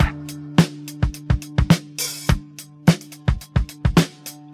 • 106 Bpm Modern Drum Loop Sample D Key.wav
Free breakbeat sample - kick tuned to the D note. Loudest frequency: 1163Hz
106-bpm-modern-drum-loop-sample-d-key-ftC.wav